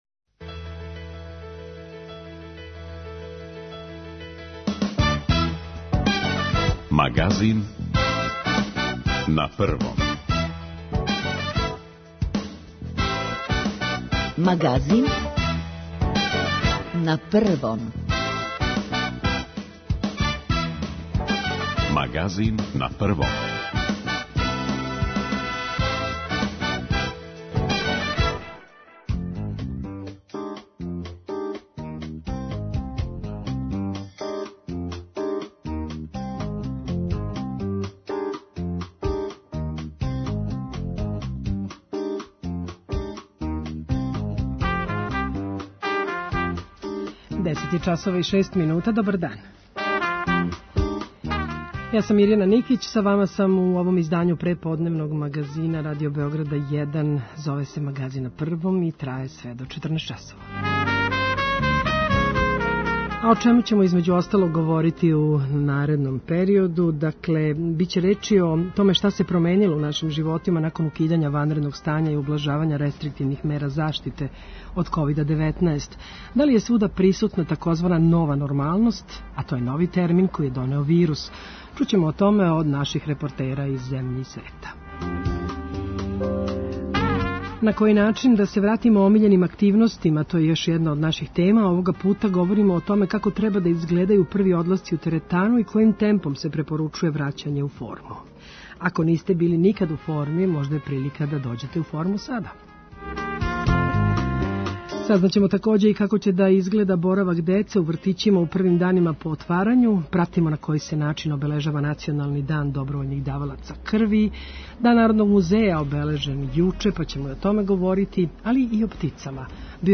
Чућемо о томе од репортера из земље и света.